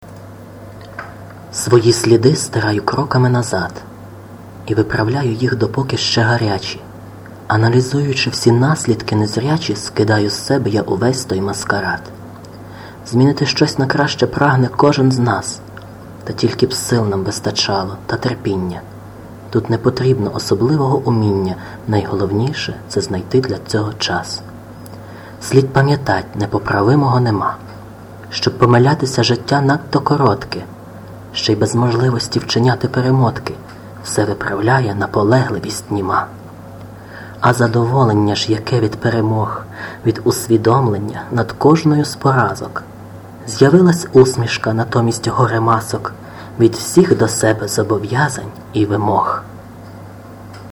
Рубрика: Поезія, Філософська лірика